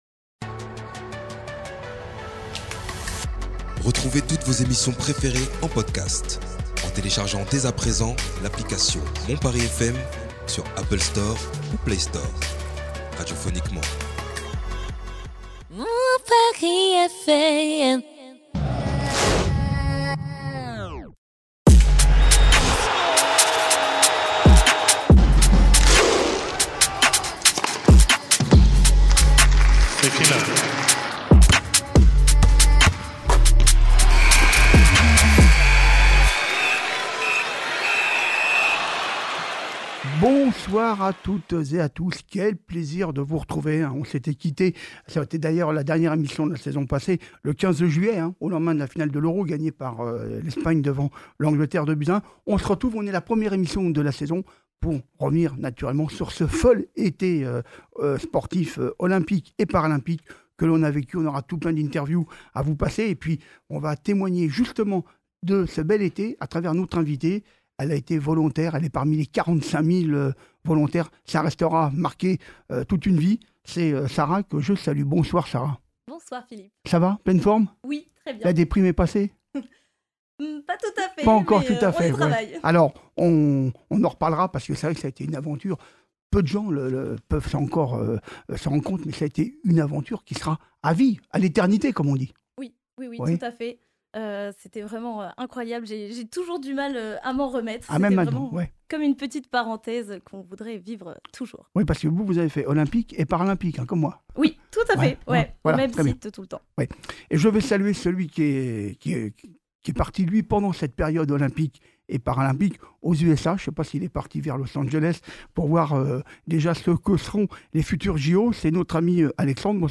Cette passionnée de sport , réagira aux différentes interviews diffusées dans l'émission (passage flamme, JO , Jeux Paras ) mais ne manquera pas aussi d'évoquer les moments intenses rencontrés lors de ses différentes missions .